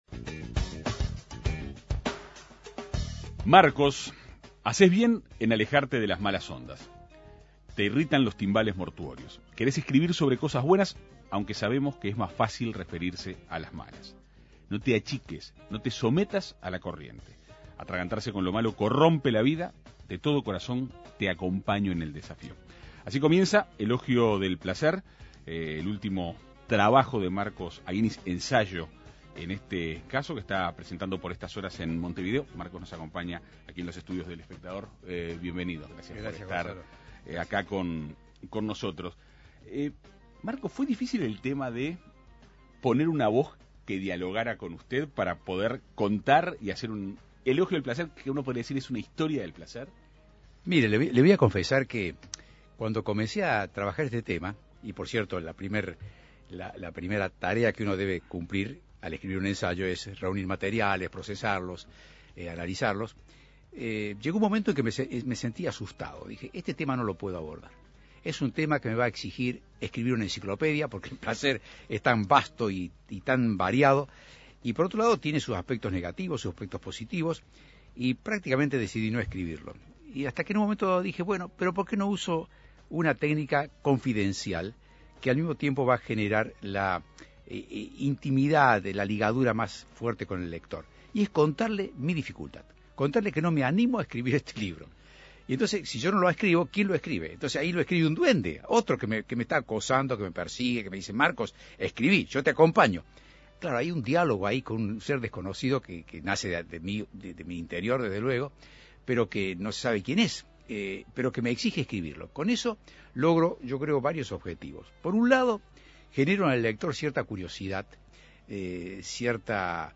Para conocer detalles de la propuesta, En Perspectiva Segunda Mañana dialogó con el escritor.